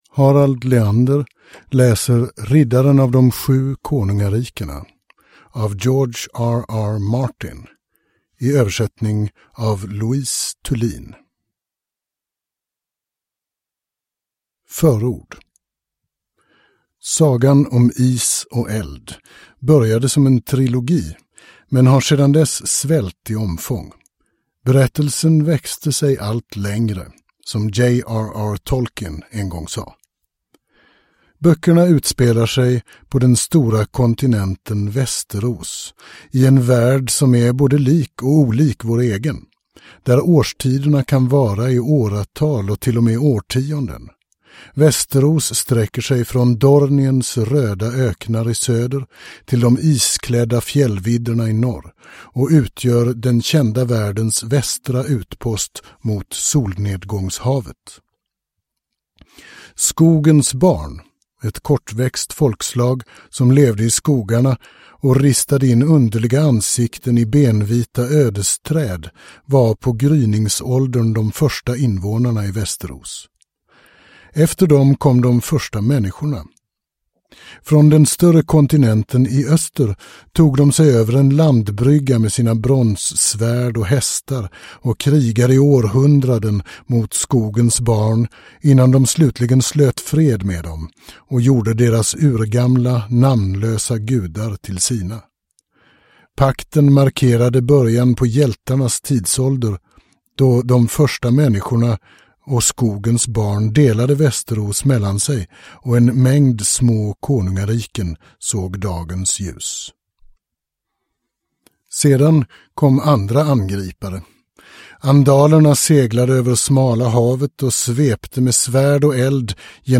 Riddaren av de sju konungarikena (ljudbok) av George R. R. Martin